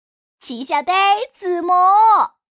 Index of /hunan_feature1/update/12822/res/sfx/changsha_woman/